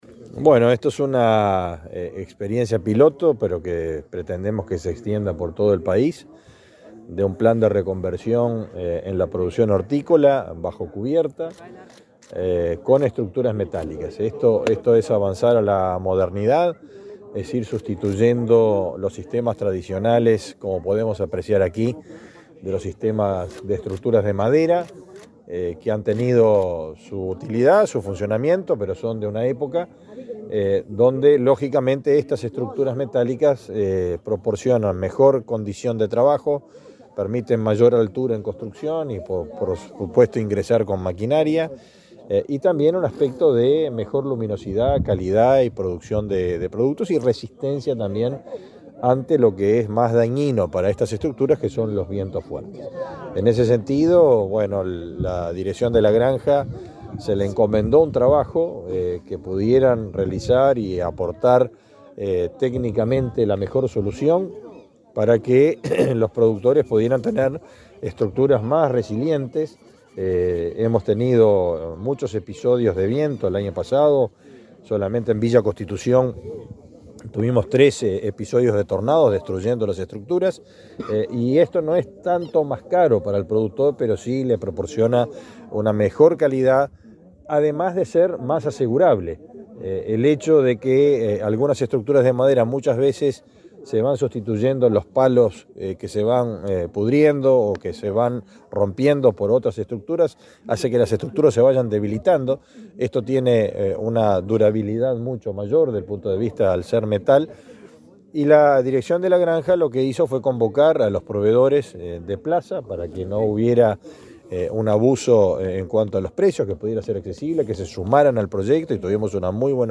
Declaraciones del ministro de Ganadería, Fernando Mattos
El ministro de Ganadería, Fernando Mattos, dialogó con la prensa, antes de participar en el lanzamiento del Plan de Apoyo a la Modernización de la
La actividad se realizó este lunes 29 en la localidad de Canelón Chico, departamento de Canelones.